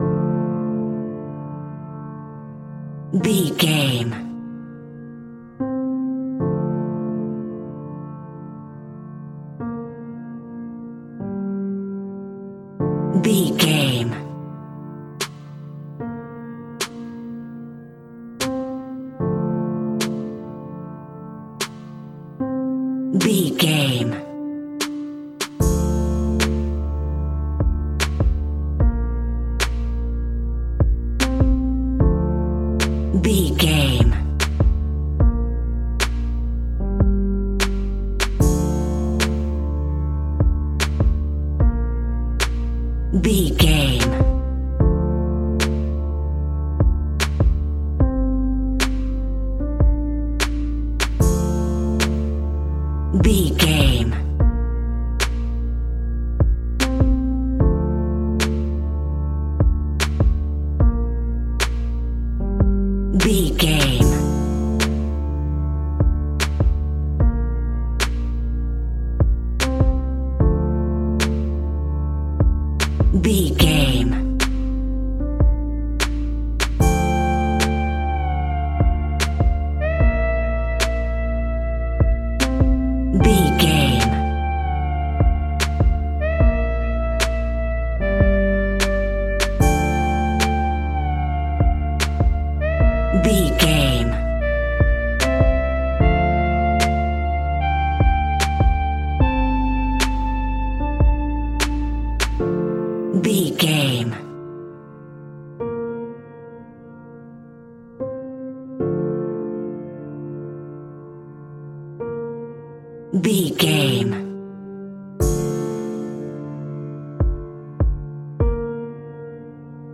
Aeolian/Minor
Slow
relaxed
tranquil
synthesiser
drum machine